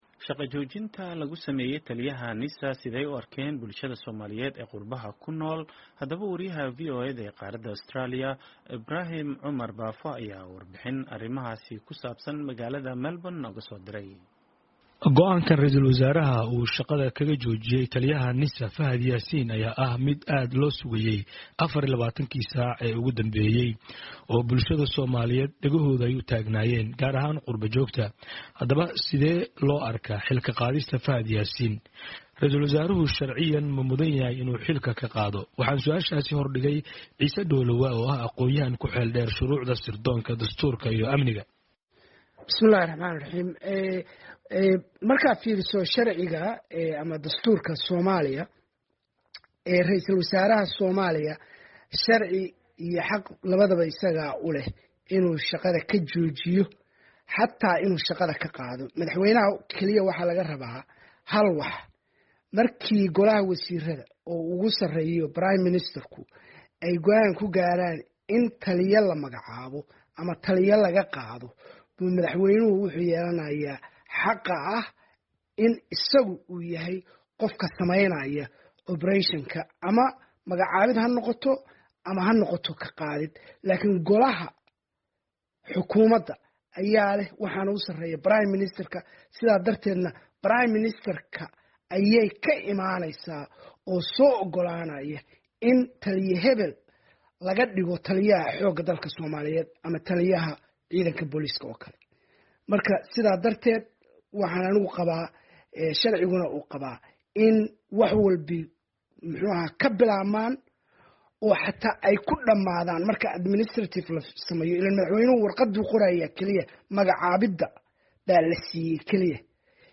Warbixin